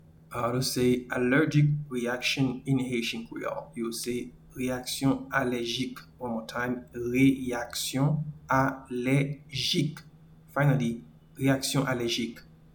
Pronunciation:
Allergic-reaction-in-Haitian-Creole-Reyaksyon-alejik.mp3